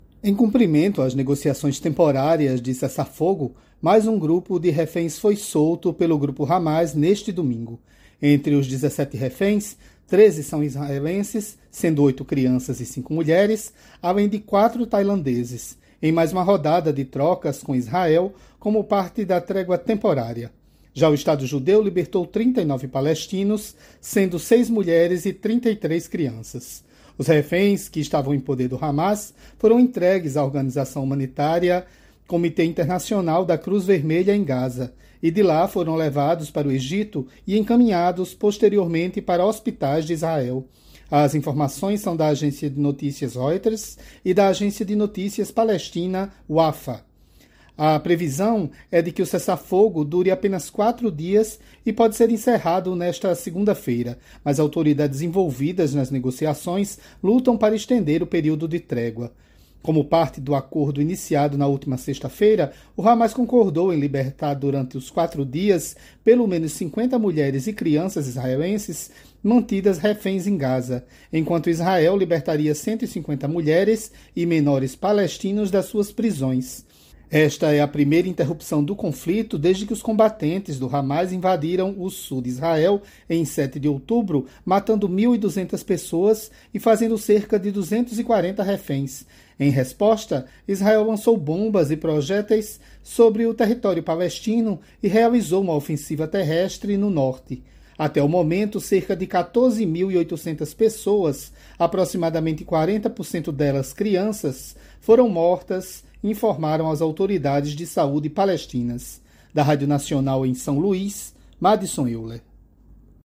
Repórter da Rádio Nacional Guerra Oriente Médio Hamas Israel domingo